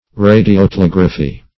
Radiotelegraphy \Ra`di*o*te*leg"ra*phy\
(r[=a]`d[i^]*[o^]*t[e^]*l[e^]g"r[.a]*f[y^]), n. [Radio- +